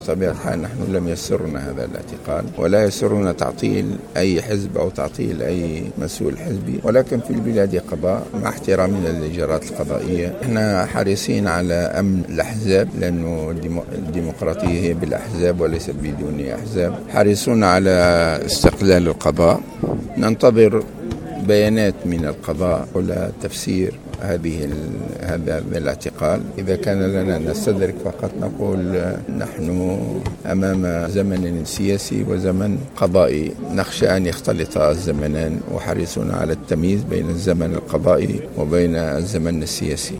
وأشار الغنوشي في تصريح اعلامي على هامش اجتماع مع شباب الحركة في أكودة، إلى انتظار القضاء ليقول كلمته في خصوص إيقاف رئيس حزب قلب تونس نبيل القروي وإعطاء تفسير لهذا الاعتقال.